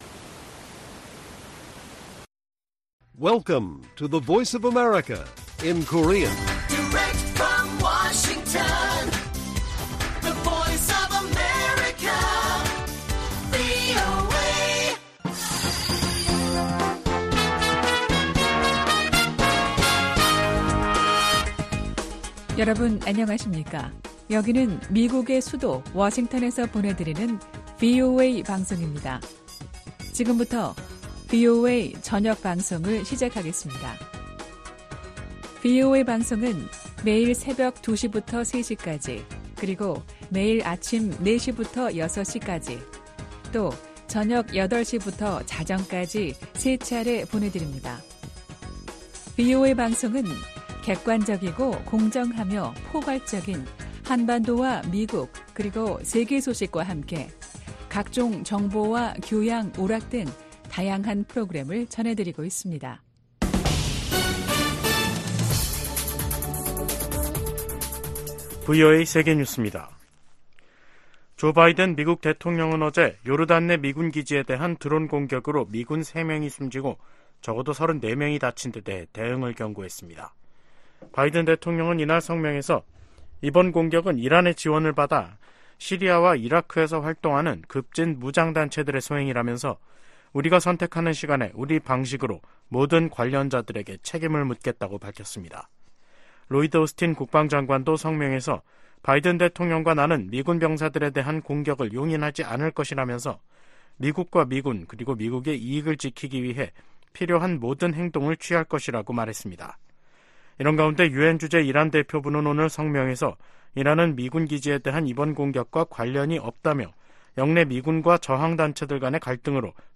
VOA 한국어 간판 뉴스 프로그램 '뉴스 투데이', 2024년 1월 29일 1부 방송입니다. 북한은 어제 시험발사한 미사일이 새로 개발한 잠수함발사 순항미사일이라고 밝혔습니다. 제이크 설리번 미국 국가안보보좌관이 왕이 중국 외교부장에게 북한의 무기실험과 북러 협력에 대한 우려를 제기했다고 미국 정부 고위당국자가 밝혔습니다. 백악관은 북한의 첨단 무기 능력 추구와 관련해 동맹국 보호 의지를 재확인했습니다.